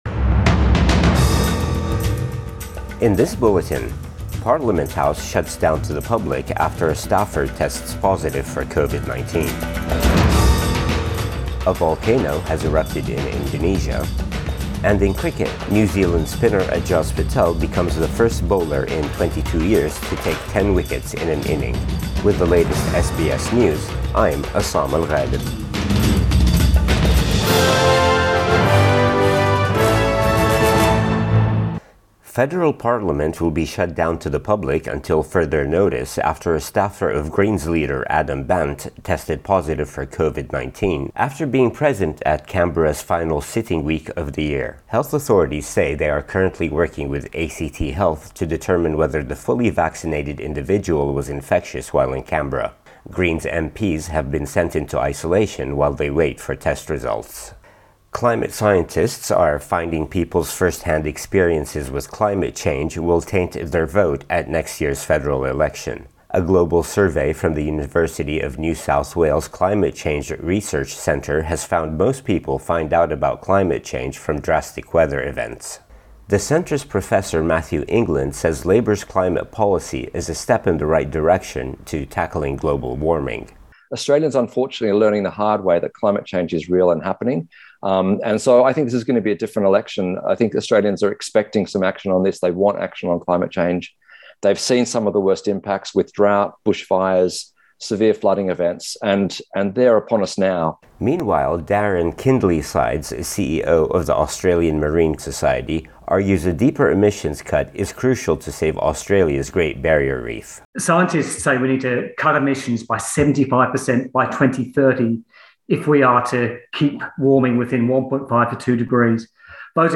AM bulletin 5 December 2021